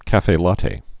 (kăfātā, käf-fĕ lättĕ)